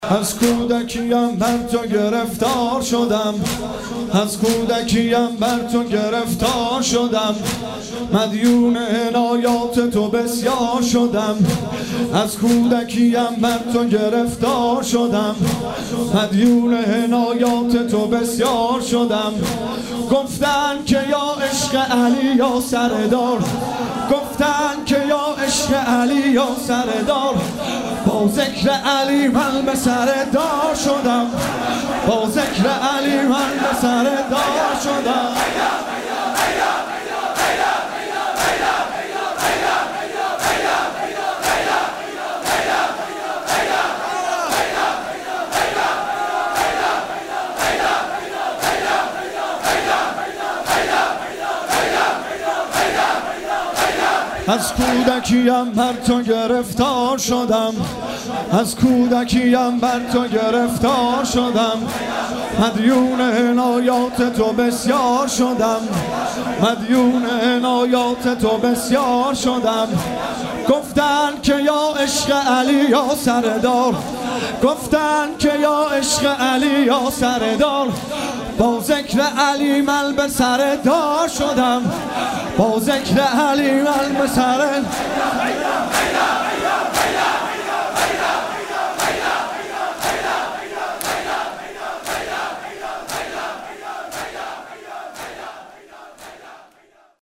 از کودکی ام برتو گرفتار شدم/شور